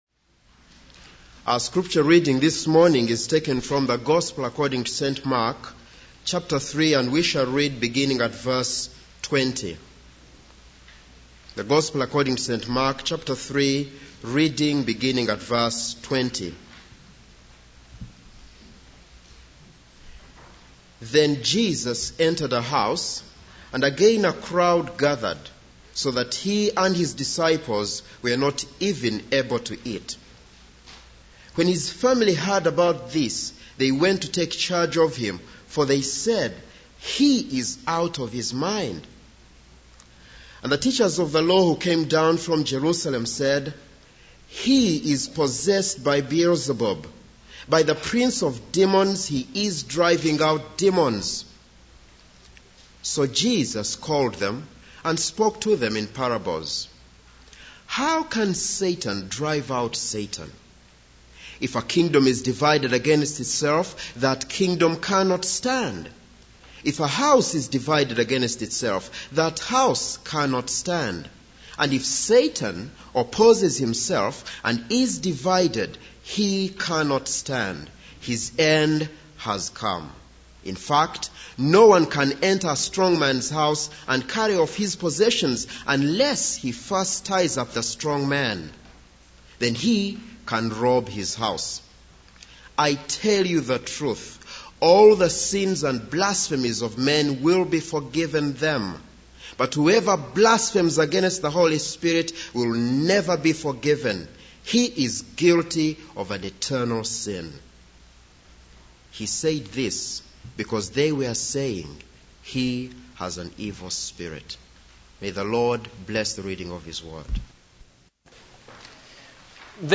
This is a sermon on Mark 3:20-30.